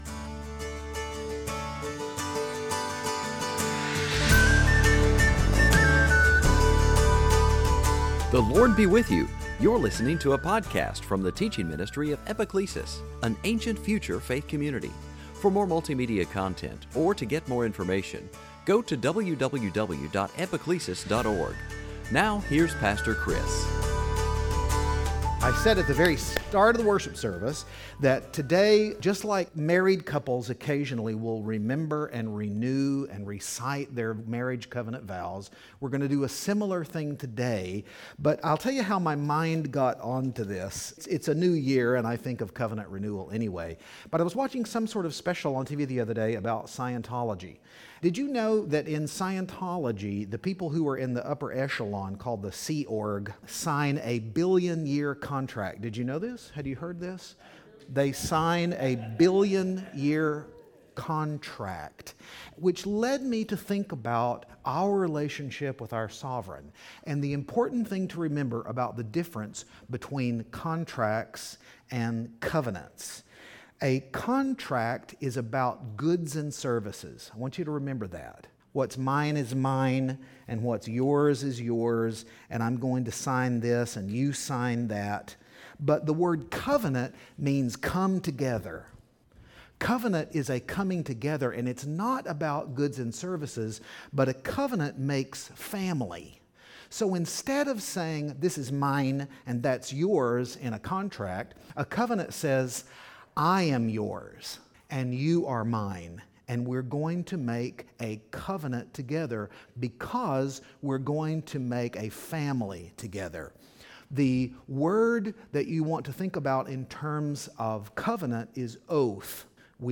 Series: Sunday Teaching On this particular Sunday morning our congregation had a Covenant Renewal Service
On this particular Sunday morning our congregation had a Covenant Renewal Service-- a time where we looked at some important covenants in Scripture, saw how they opened increasingly wider the curtains on God's salvation history, and then pledged ourselves anew to God as we renewed our vows to Him.